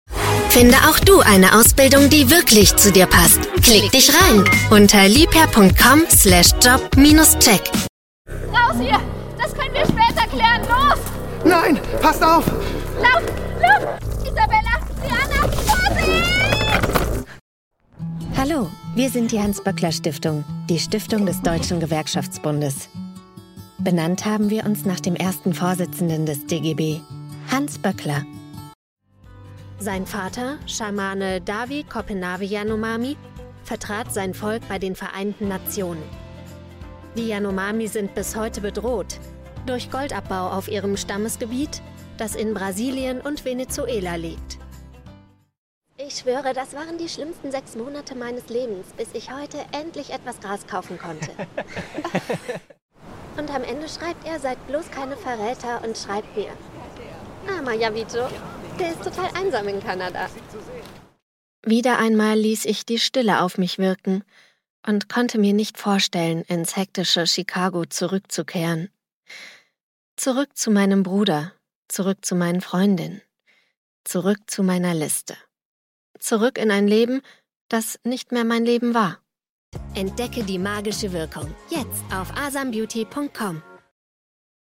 Female
Bright, Character, Cheeky, Confident, Cool, Corporate, Friendly, Natural, Soft, Warm, Versatile, Young, Approachable, Assured, Authoritative, Bubbly, Conversational, Energetic, Engaging, Funny, Posh, Reassuring, Sarcastic, Smooth, Streetwise, Upbeat
My fresh and young, yet warm voice is often described as special and trustworthy at the same time.
Microphone: Neumann TLM49